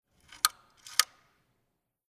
24112_Anschalten.mp3